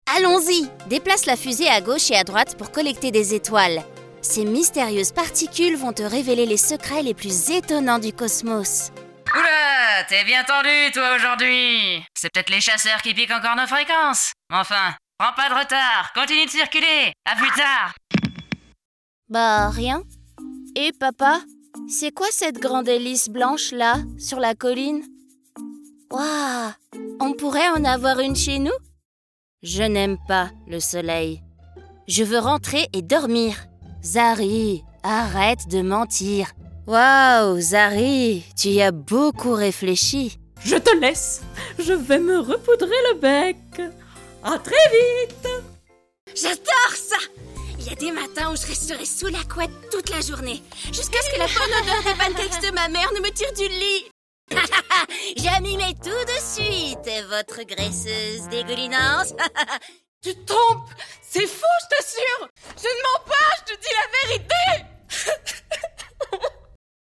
Natural, Versátil, Amable